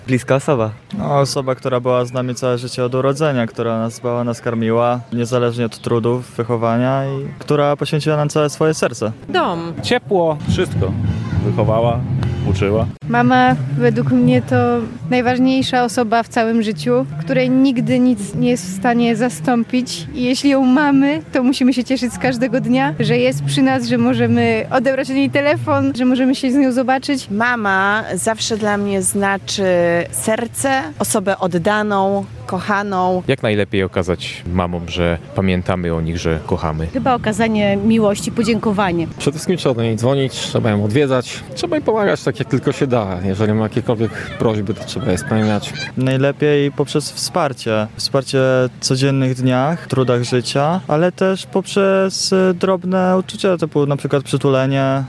Dzień Matki. Za co kochamy nasze mamy? [SONDA]